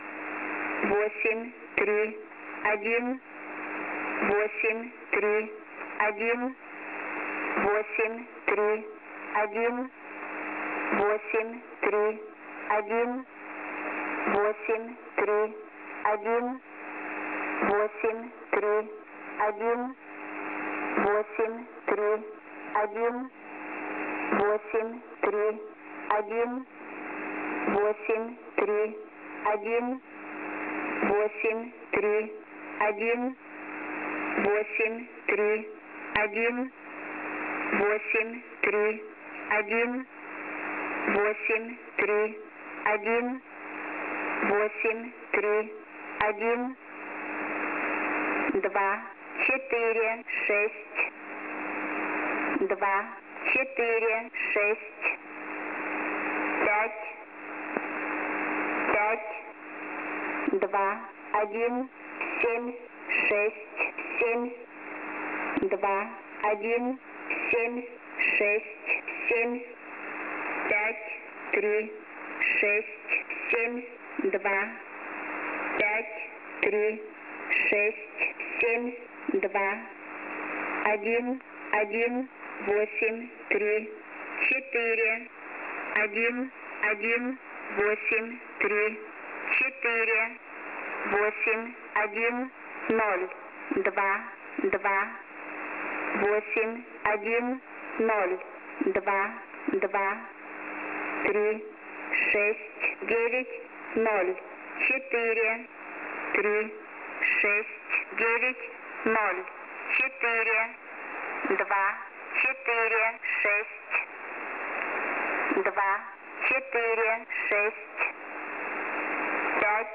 Numerstation S06s on 12165 khz